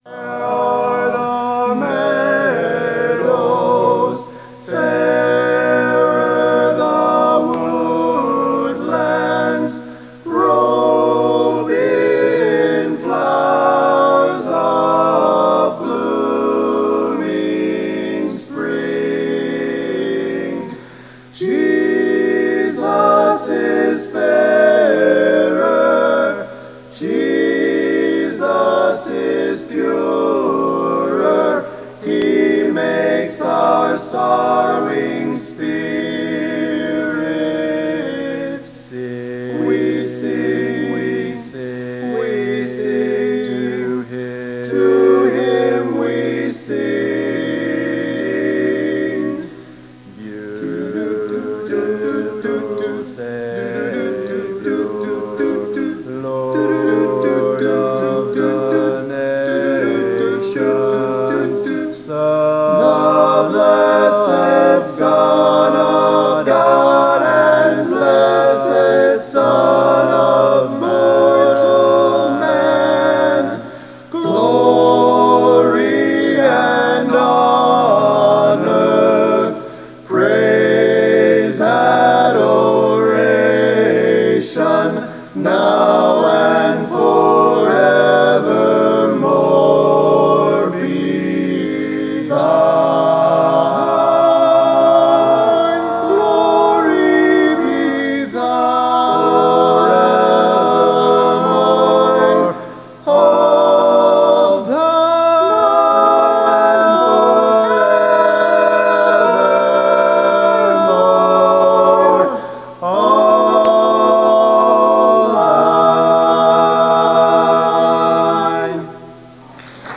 They only rehearsed four or five times, but they still sounded pretty good.
Here are recordings (of okay quality) of the songs they sang: